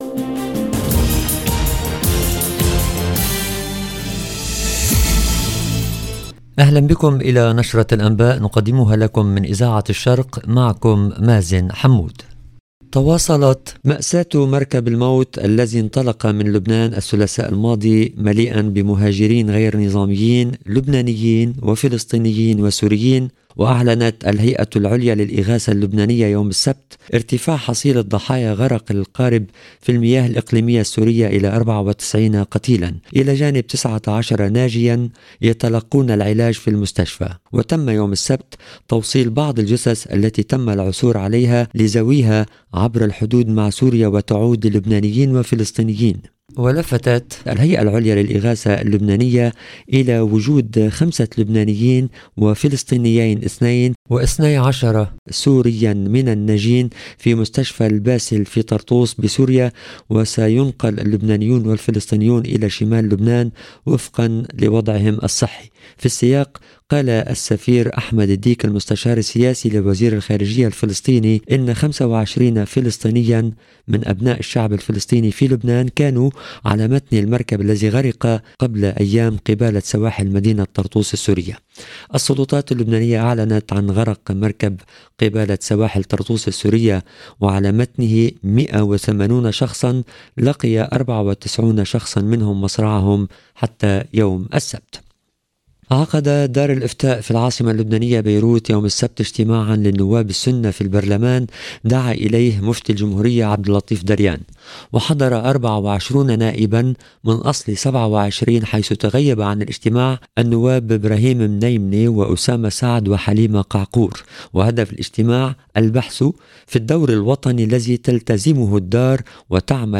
EDITION DU JOURNAL DU SOIR EN LANGUE ARABE DU 25/9/2022